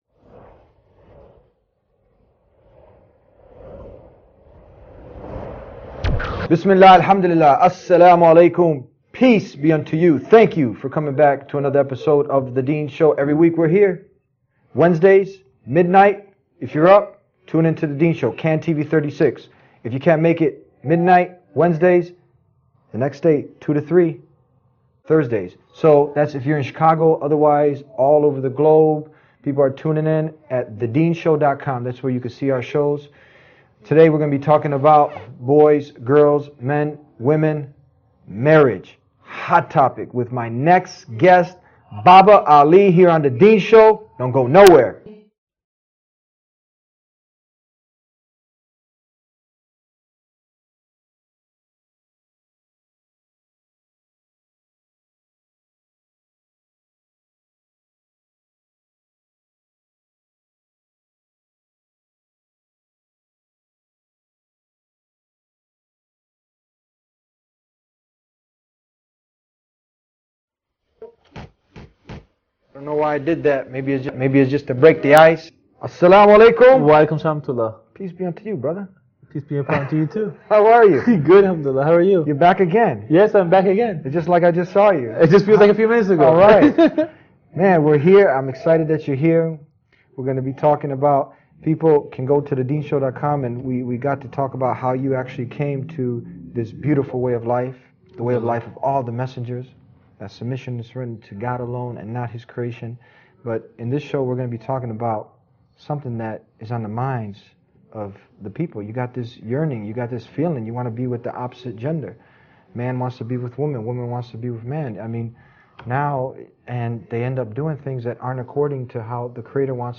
In this candid conversation, the realities of dating culture, parental obstacles, and the Islamic approach to finding a spouse are discussed with refreshing honesty — offering practical advice for anyone seeking to do things the right way.